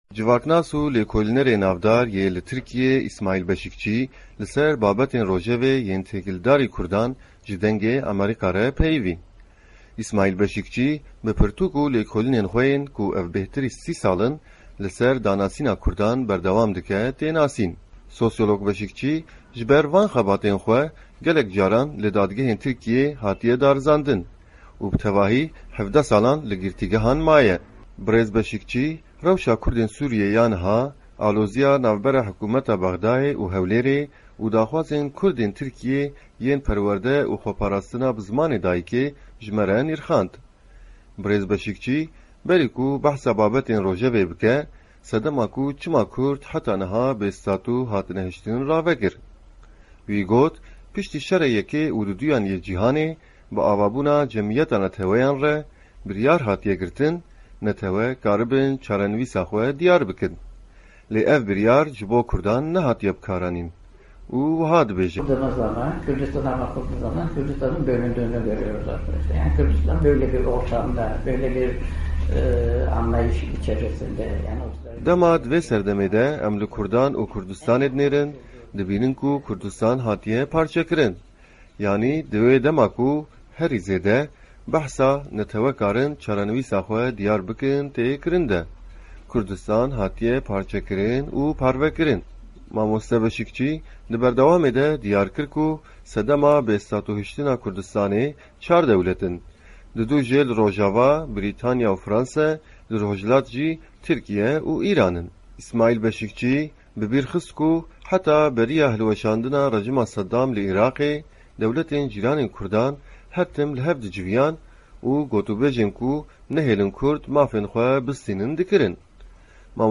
Hevpeyvîn bi Îsmaîl Beşîkçî re